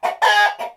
loudcluck2.wav